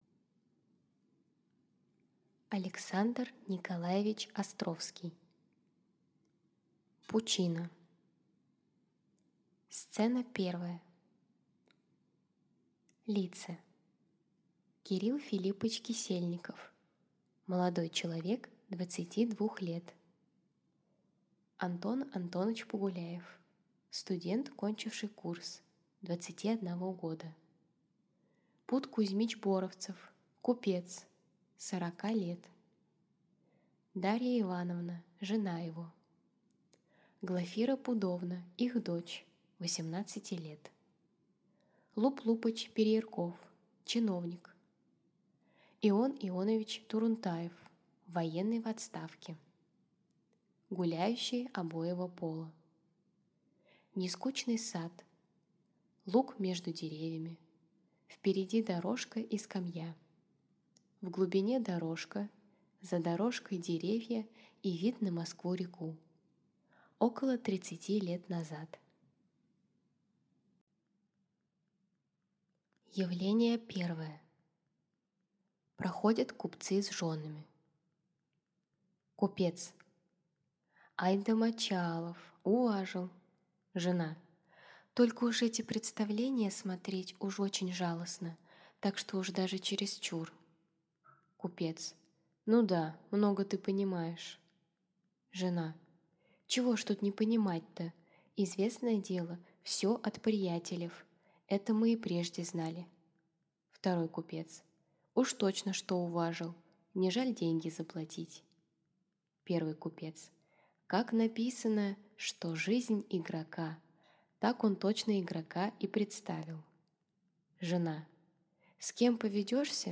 Аудиокнига Пучина | Библиотека аудиокниг